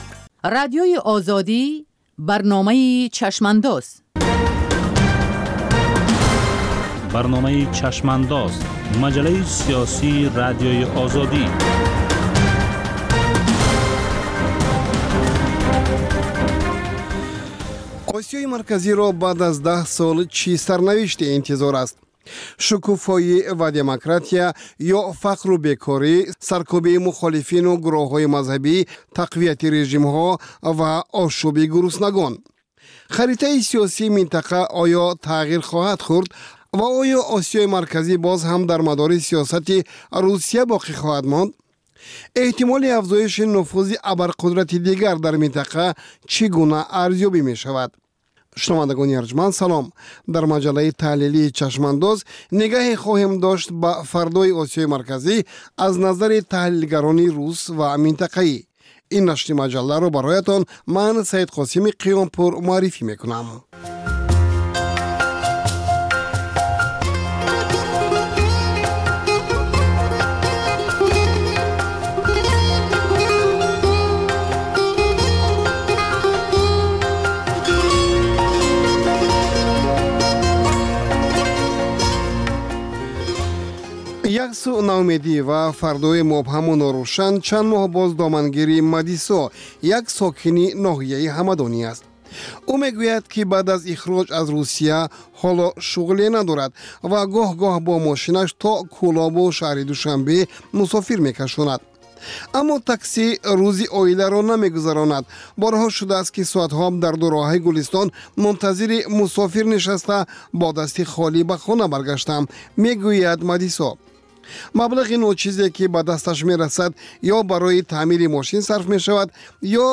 Сӯҳбати ошкоро бо чеҳраҳои саршинос ва мӯътабари Тоҷикистон дар мавзӯъҳои гуногун, аз ҷумла зиндагии хусусӣ.